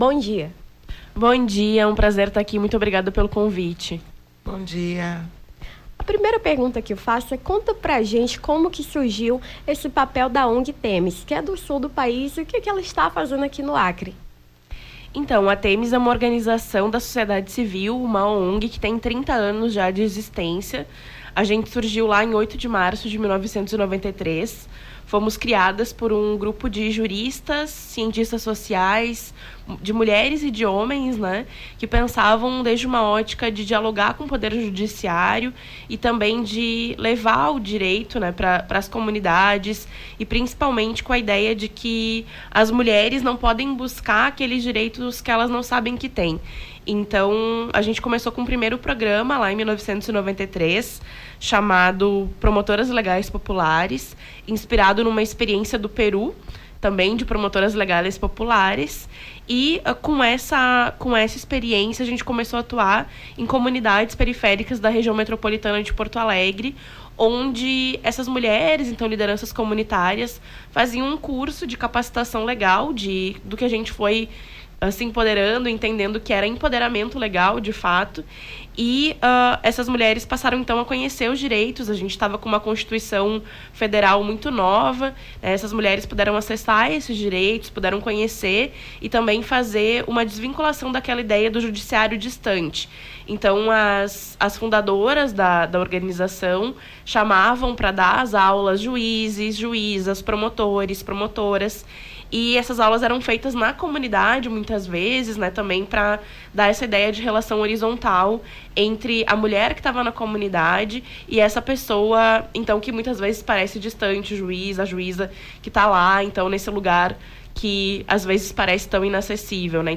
Nome do Artista - CENSURA - EMTREVISTA (PROJETO ALFABTEIZACAO DOMESTICAS) 10-07-23.mp3